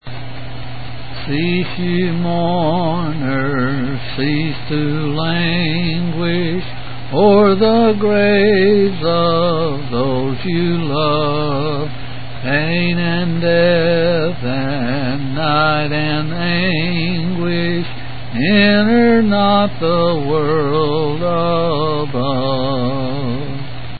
8s and 7s